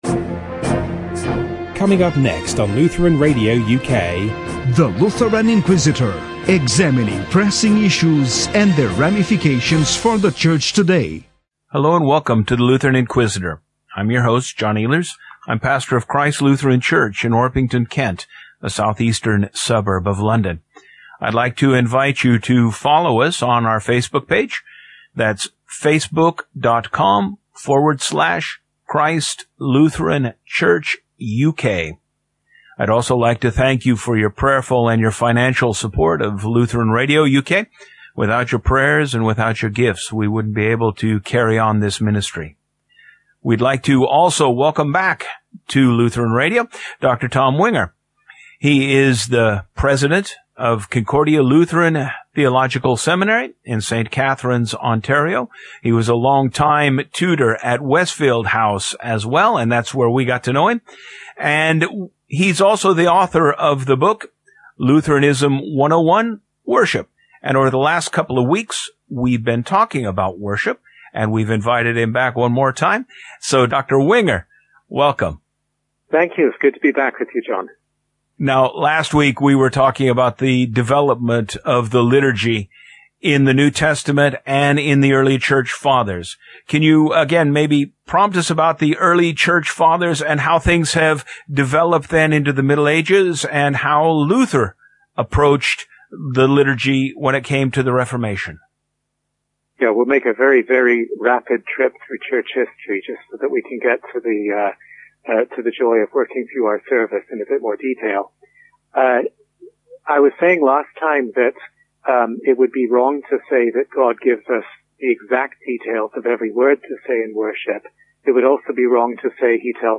Lutheranism 101: Worship Interview, Part 3